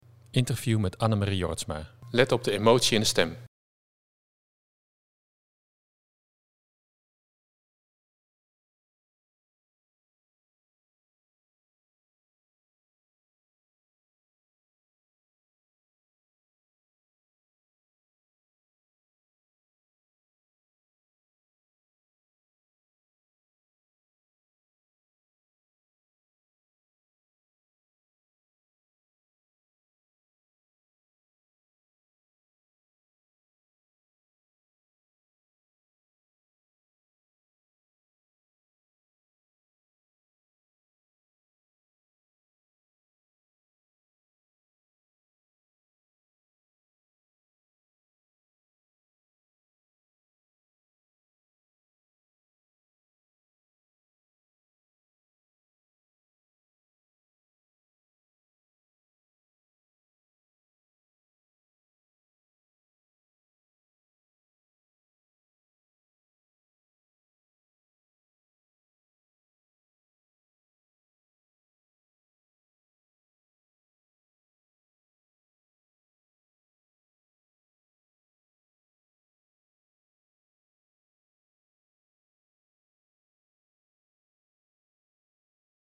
Interview met Annermarie Jorritsma.